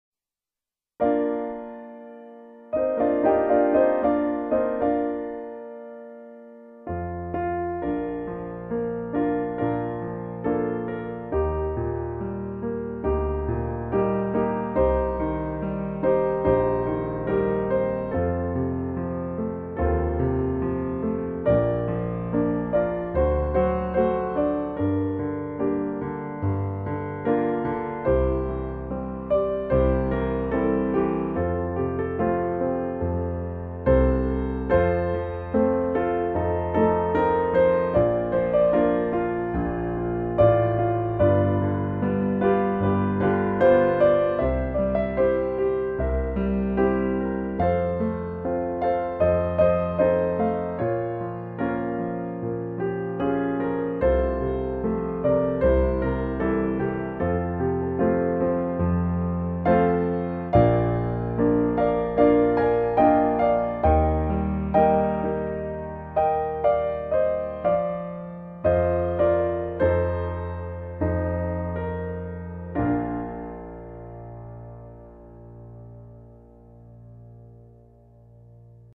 Музыка для грузинских патриотов на пианино